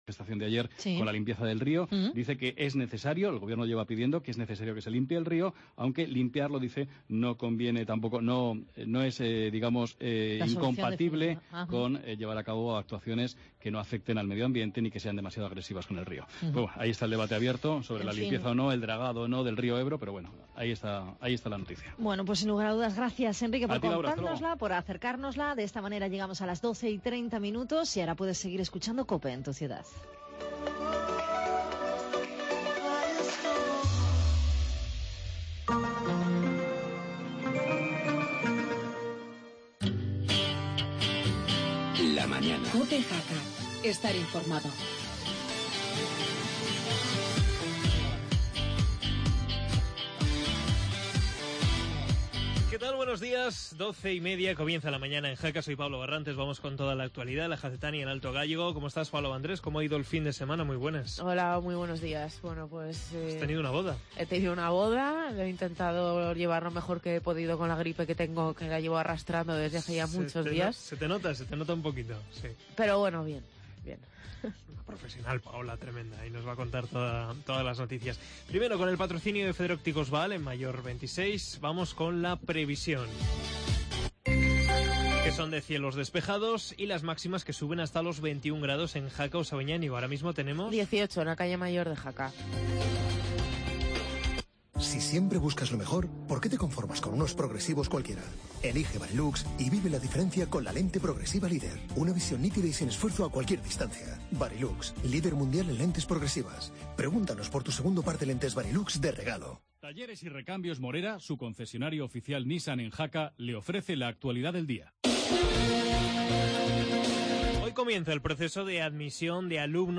entrevista a responsables de Acomseja